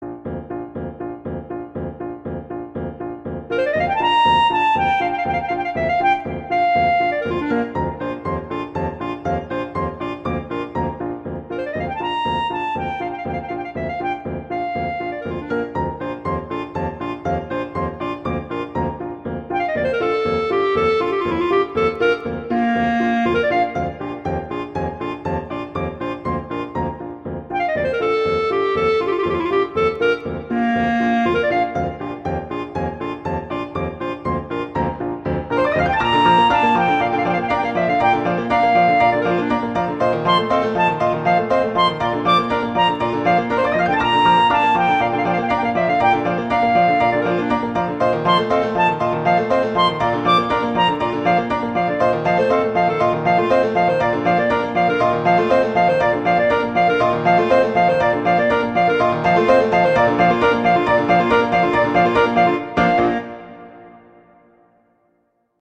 arrangement for clarinet and piano
clarinet and piano
classical, holiday